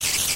sounds / mob / spider